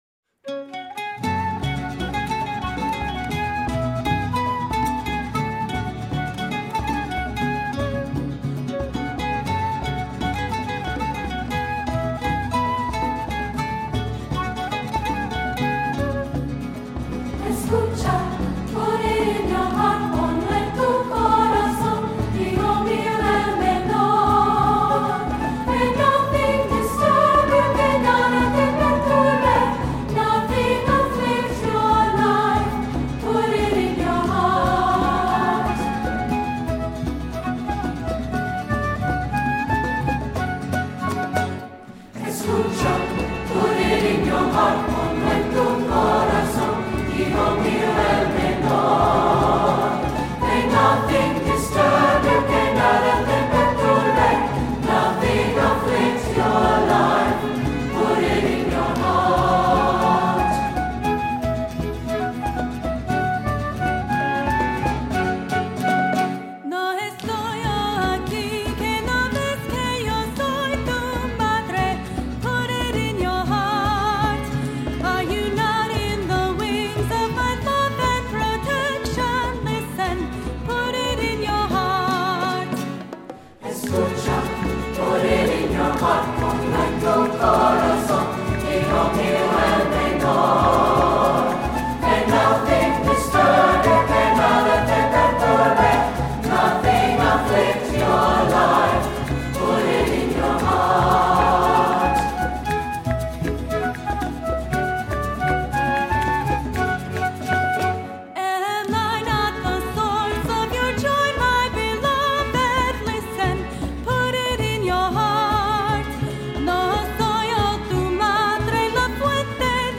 Voicing: Two-Part; Descant; Cantor; Assembly